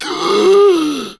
hgasp1.wav